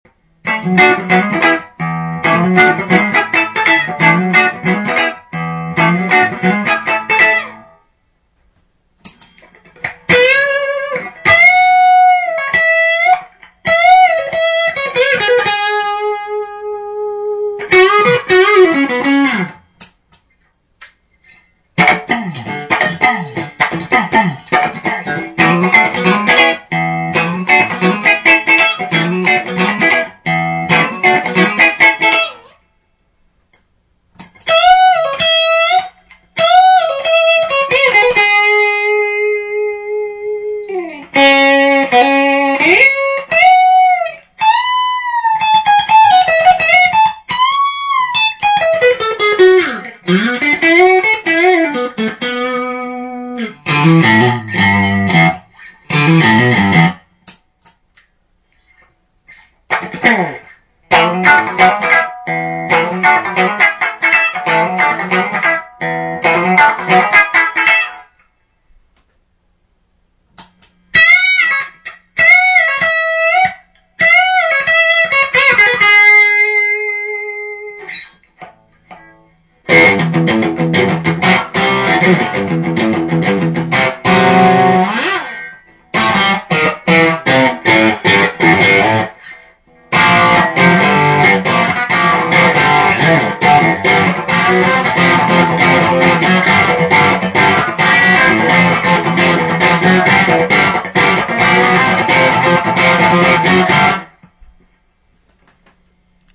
ギターからモガミのシールドケーブル(5m・スイッチプラグ付)→Carl MartinのHot Drive'n Boost→Whirlwindのシールドケーブル(1m)→Two-Rock Topazの順です。
ええ、家庭音量です…
で、比較しやすいようシングルコイル2発搭載でアーム無しのギターにしました。前述の機材でクリーンとドライヴチャンネルを切り替えつつ、フロントからミックス、リアって感じで進めました。